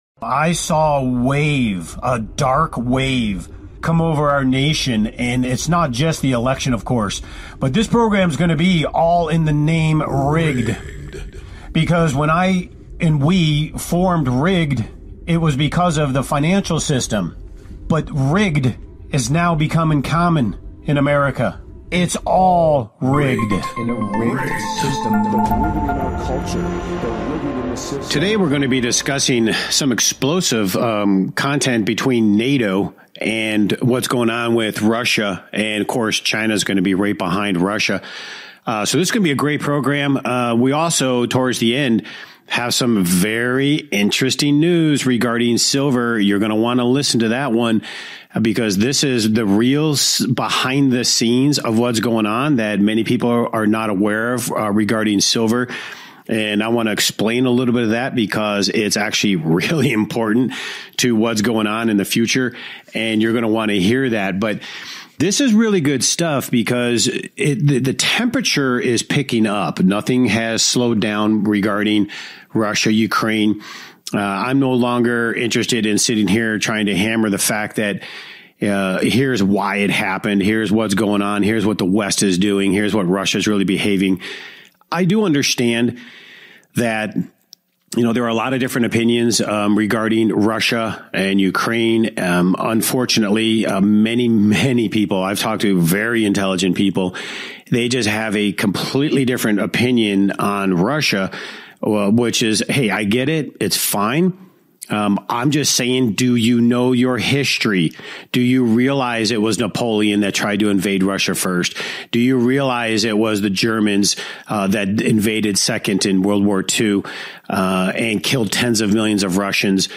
Talk Show Episode, Audio Podcast, Rigged Against You and The Russia NATO Problem on , show guests , about The Russia NATO Problem, categorized as Business,Investing and Finance,History,Military,News,Politics & Government,Society and Culture,Technology